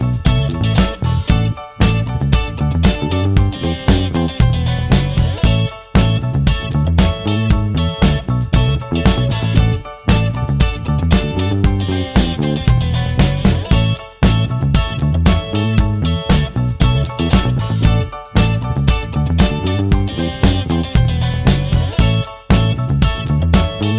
No Backing Vocals Christmas 5:22 Buy £1.50